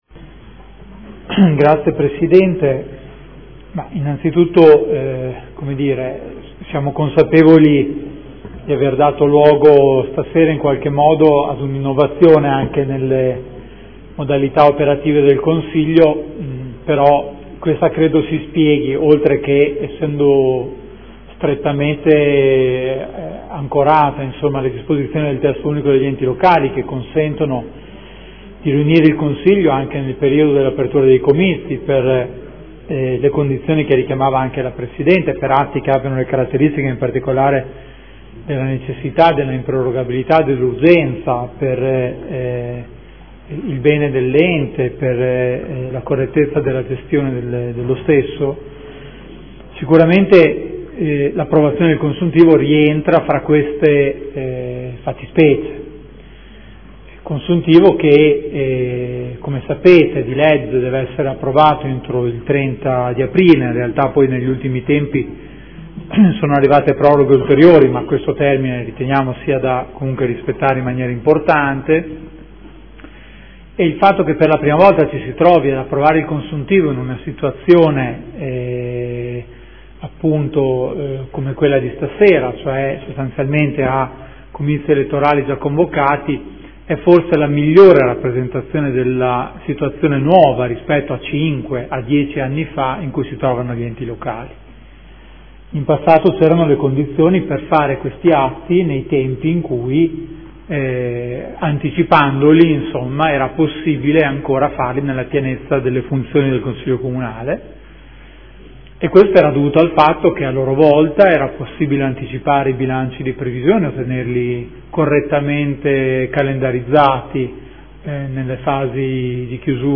Seduta del 28/04/2014 Rendiconto della gestione del Comune di Modena per l’esercizio 2013 - Approvazione